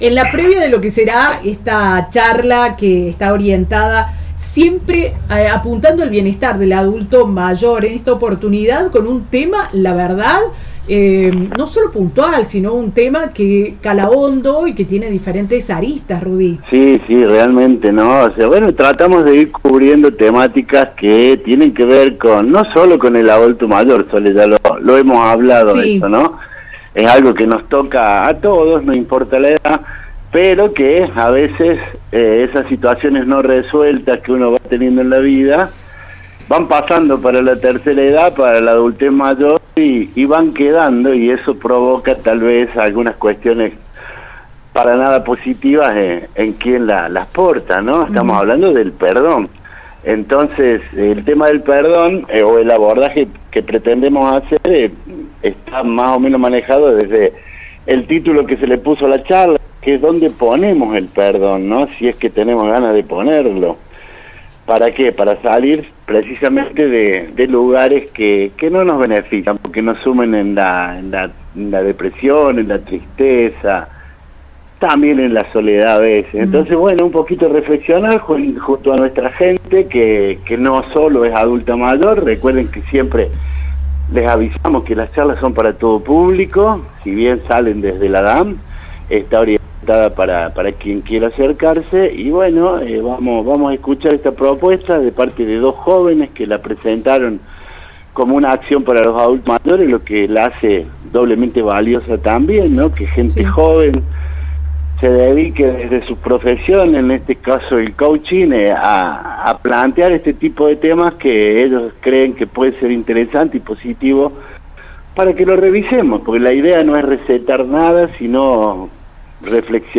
explicó en diálogo con la radio que esta actividad busca abrir un espacio de reflexión sobre situaciones no resueltas que con el paso del tiempo pueden afectar el bienestar emocional